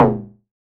RDM_TapeA_SY1-Perc02.wav